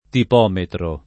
tipometro [ tip 0 metro ] s. m.